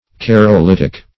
carolitic - definition of carolitic - synonyms, pronunciation, spelling from Free Dictionary Search Result for " carolitic" : The Collaborative International Dictionary of English v.0.48: Carolitic \Car`o*lit"ic\, a. (Arch.)